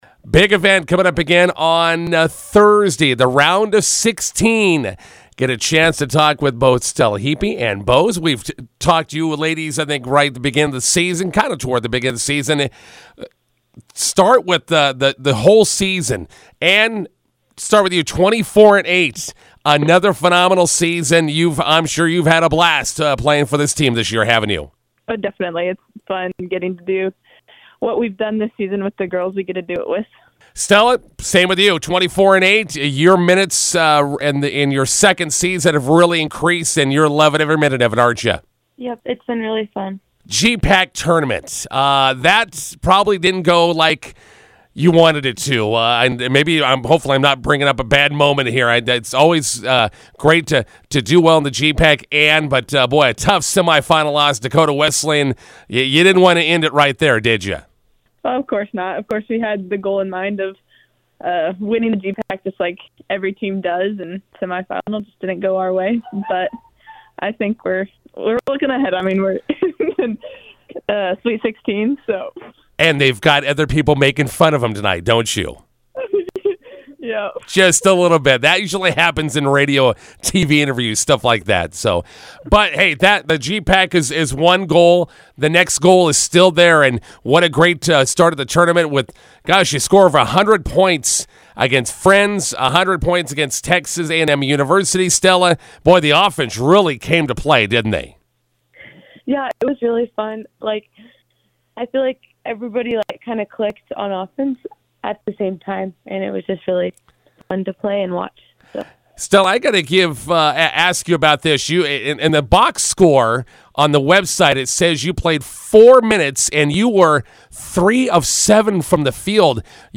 INTERVIEW: Hastings College women's basketball punch ticket the NAIA Sweet 16 round.